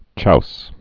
(chous, choush)